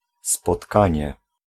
Ääntäminen
Synonyymit chère Ääntäminen France: IPA: [a.bɔʁ] Haettu sana löytyi näillä lähdekielillä: ranska Käännös Ääninäyte Substantiivit 1. spotkanie {m} Suku: m .